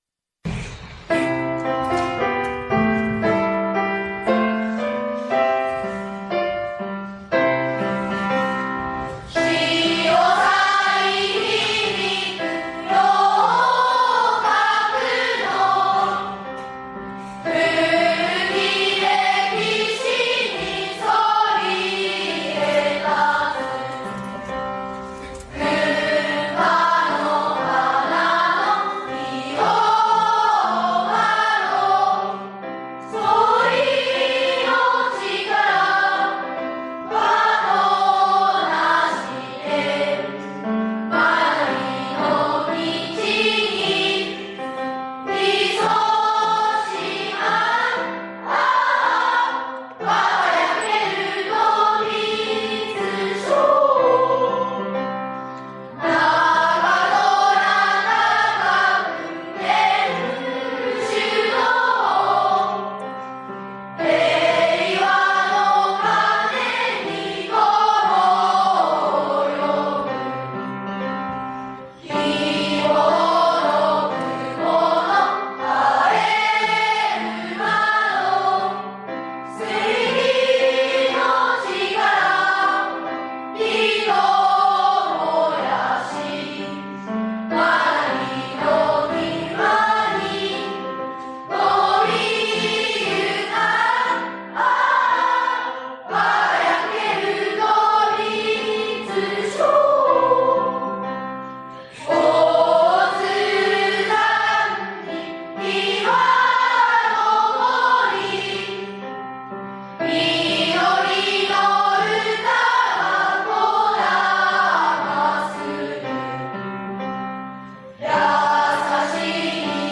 現行政区  旧行政区 No 小学校名  校歌楽譜・歌詞・概要  校歌音源（歌・伴奏） 　　備考